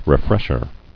[re·fresh·er]